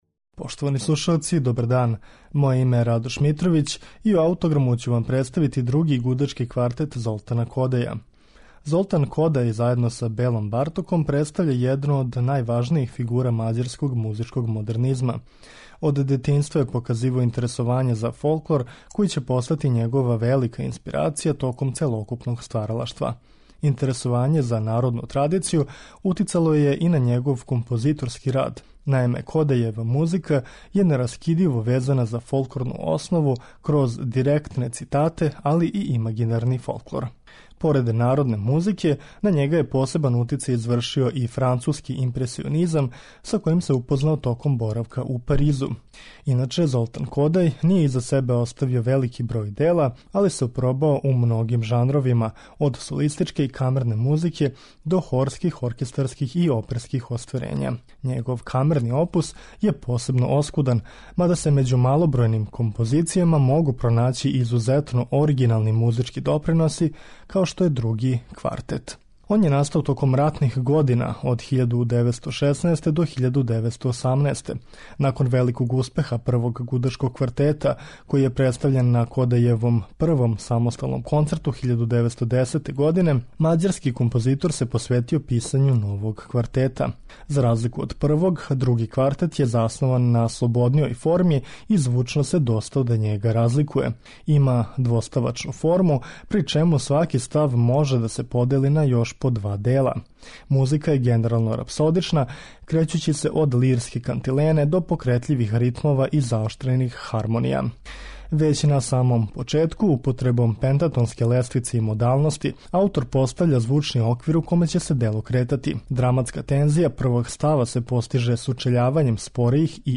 Ово дело садржи све значајне елементе његовог језика: импресионистичку боју, експресионистички призвук и уплив фолклорних елемената. "Други квартет" Золтана Кодаља представићемо вам у извођењу квартета Данте.